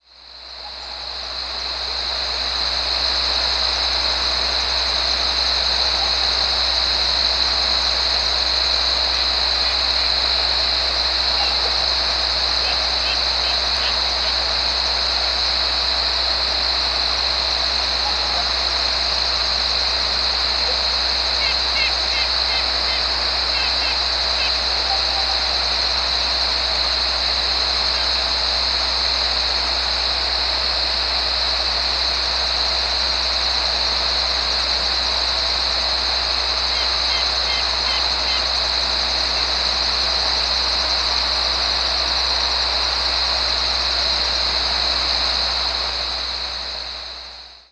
presumed Red-breasted Nuthatch nocturnal flight calls
Nocturnal flight call sequences: